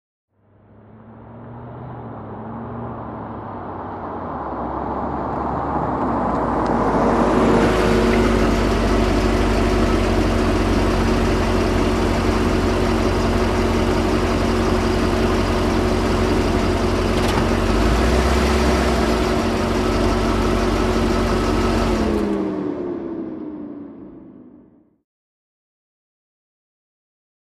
Chevy Corvette
Corvette; In / Stop / Off; Approach With Tire Whine, Some Debris Under Tires. Idle With Clicking In Metallic Chamber, Transmission Grind And Off With Wind Down Of Ventilation System. Medium To Close Per